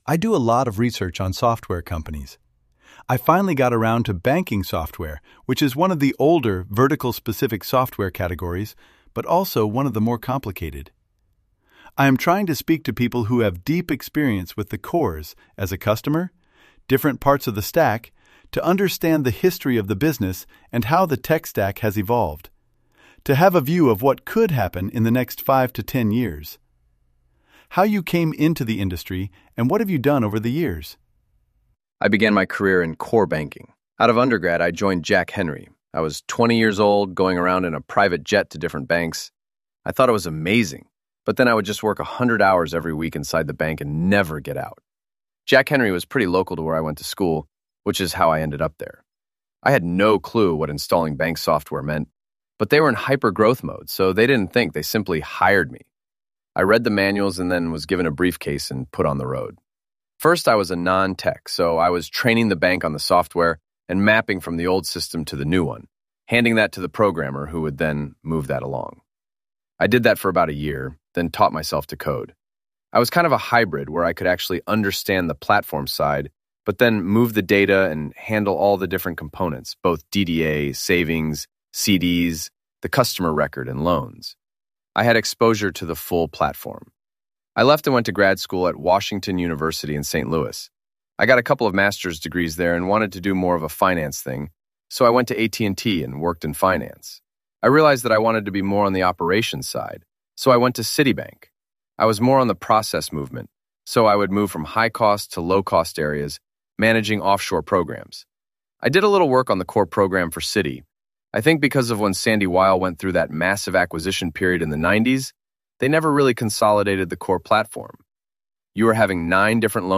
In Practise Interviews